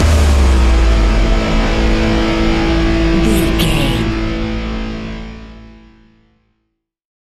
Ionian/Major
hard rock
heavy metal
instrumentals